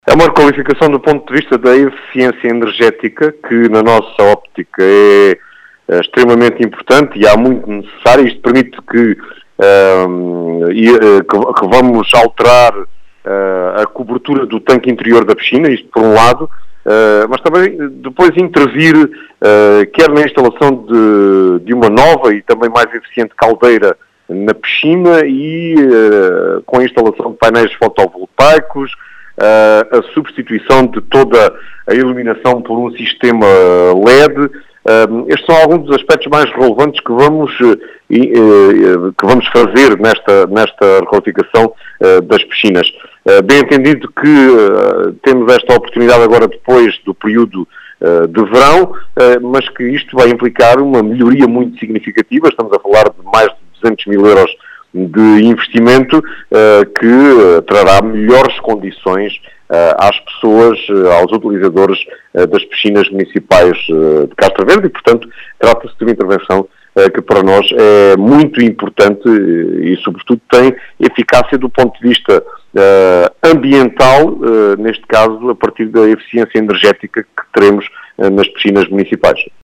As explicações são de António José Brito, presidente da Câmara Municipal de Castro Verde, fala de uma intervenção “importante”.